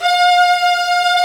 Index of /90_sSampleCDs/Roland L-CD702/VOL-1/CMB_Combos 2/CMB_Hi Strings 3
STR VIOLIN02.wav